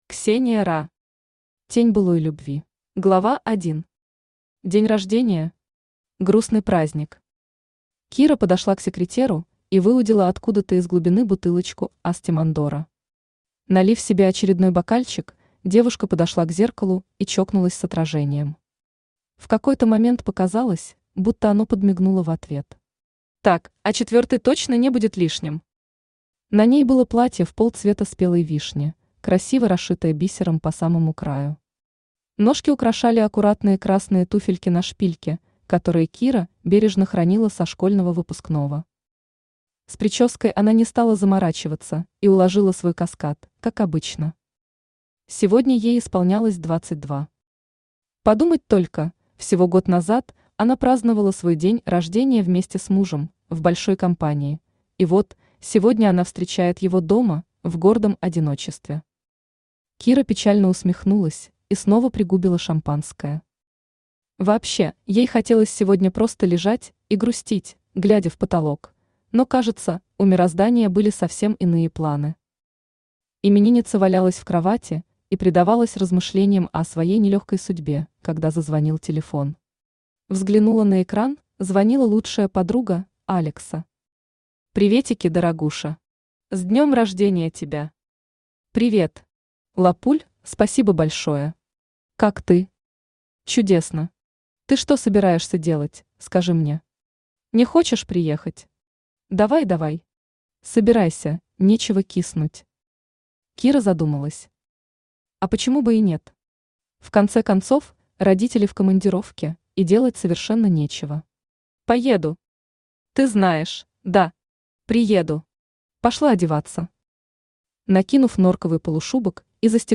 Аудиокнига Тень былой любви | Библиотека аудиокниг
Aудиокнига Тень былой любви Автор Ксения Ра Читает аудиокнигу Авточтец ЛитРес.